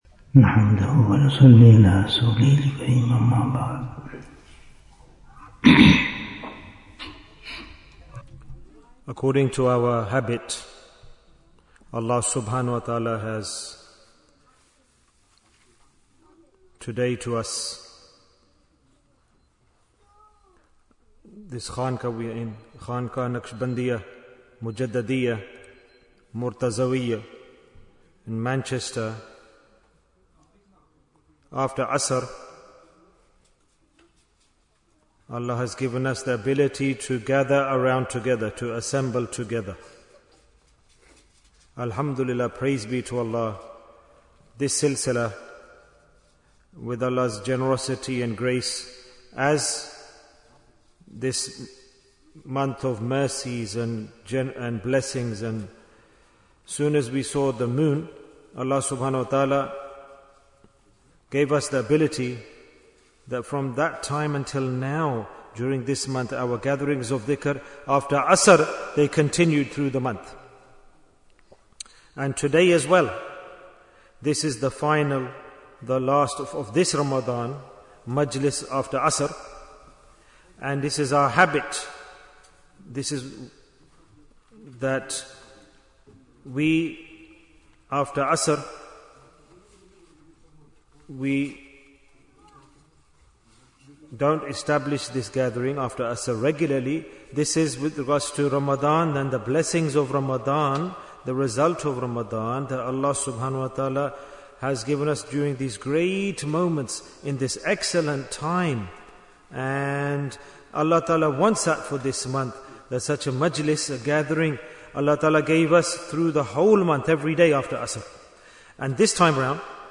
Jewels of Ramadhan 2025 - Episode 48 - The Last Asharah in Manchester Bayan, 13 minutes29th March, 2025